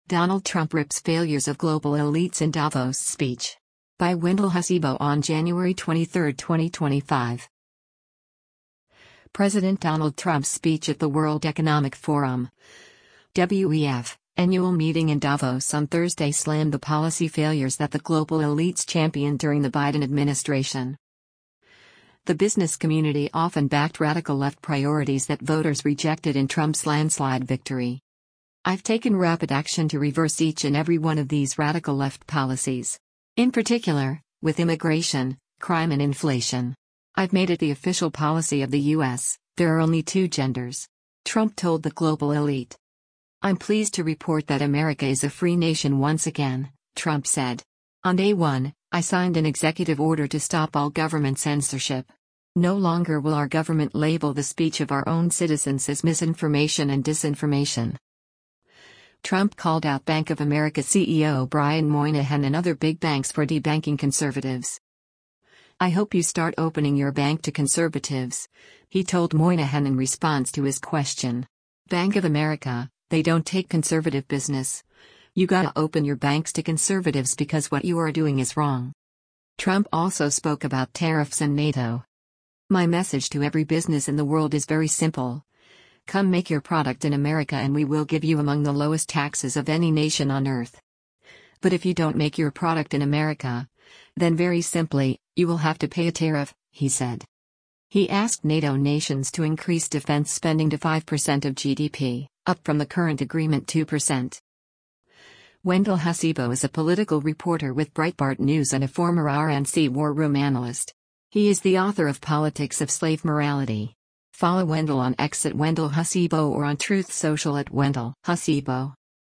President Donald Trump’s speech at the World Economic Forum (WEF) annual meeting in Davos on Thursday slammed the policy failures that the global elites championed during the Biden administration.